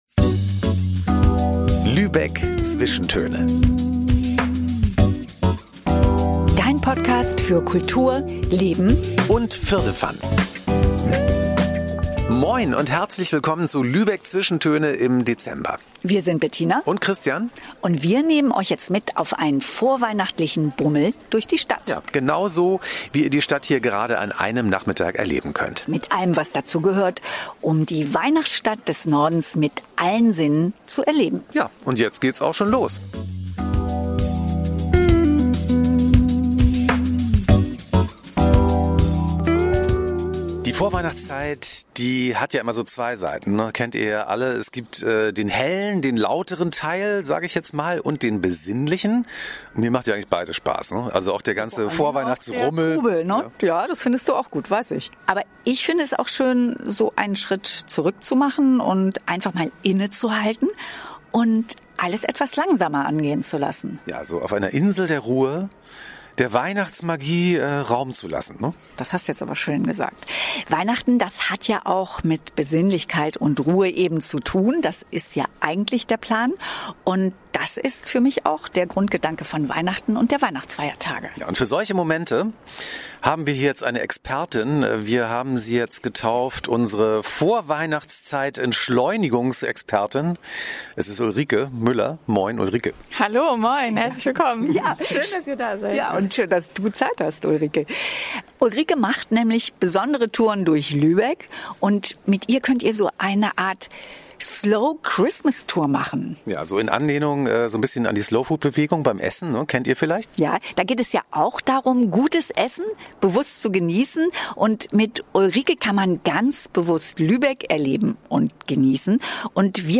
Wir nehmen dich mit auf einen akustischen Bummel durch das sehr vorweihnachtliche Lübeck – durch die lebendigen Weihnachtswelten und die festlich stillen Gassen und Hinterhöfe. Wir verraten dir, wo du ein geheimes Backparadies findest und wo du die Weihnachtgeschichte mal ganz anders erleben kannst. Es gibt mit Liebe gemachte Spätzle aus Wien und besonderen Apfelpunsch.